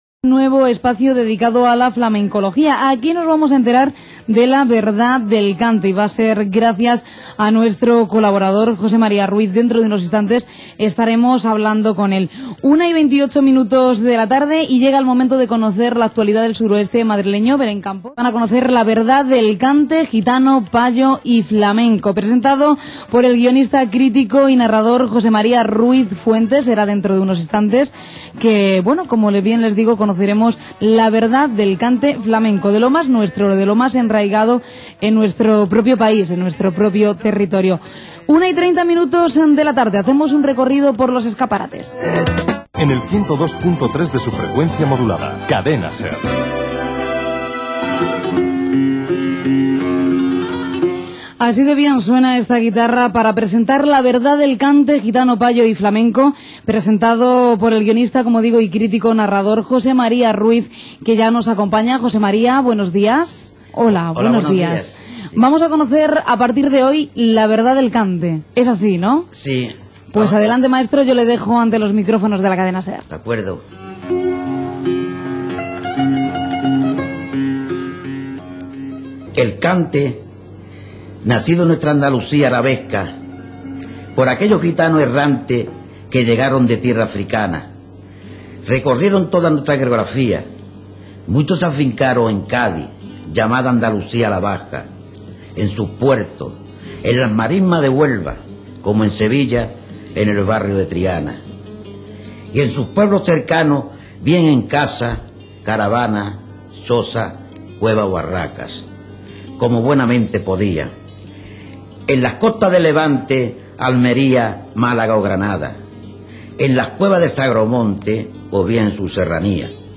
BULERIAS